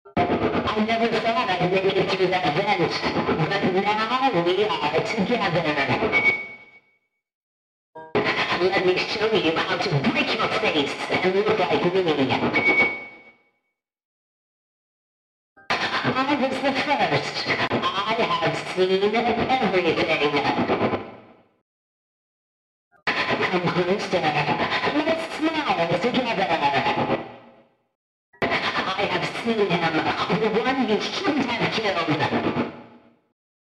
Withered Chica's voice lines animated sound effects free download